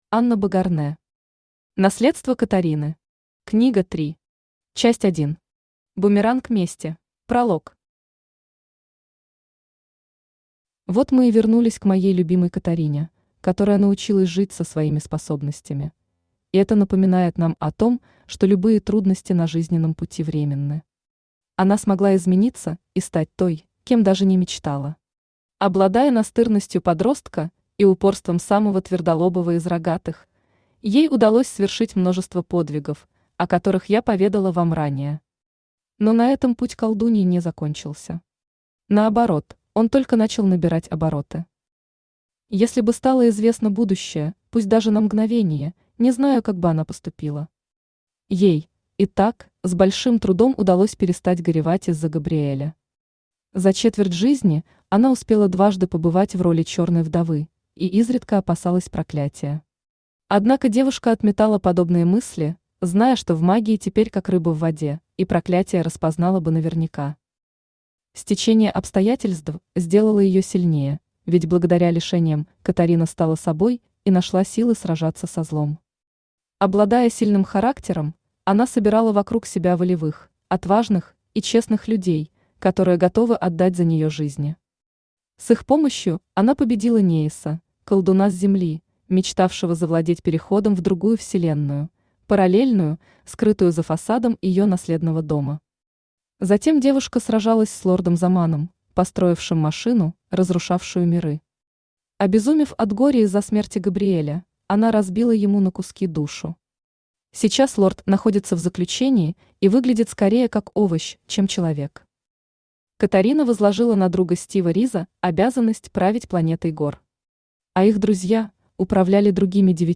Аудиокнига Наследство Катарины. Книга 3. Часть 1. Бумеранг мести | Библиотека аудиокниг
Бумеранг мести Автор Анна Богарнэ Читает аудиокнигу Авточтец ЛитРес.